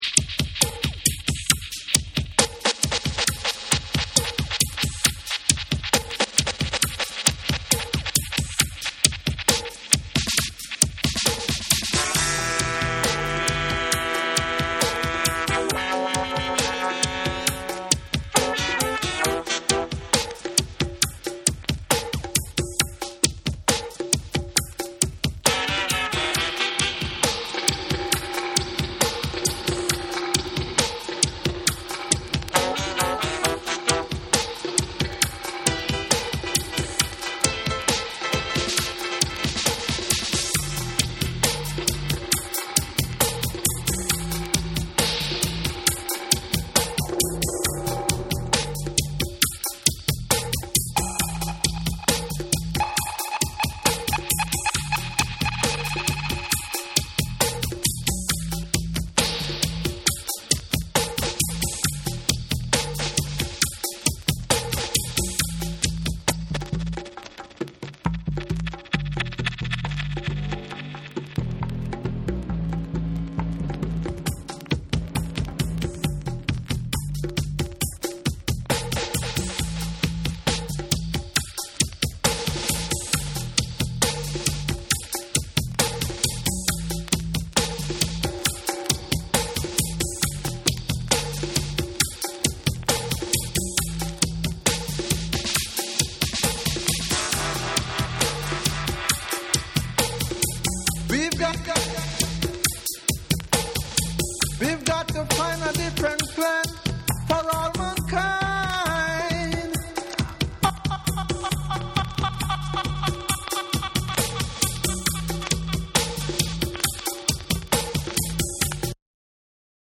※チリノイズあり。